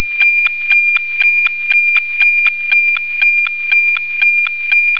NOAA weather satellite